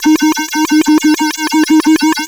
OSCAR 14 D#1.wav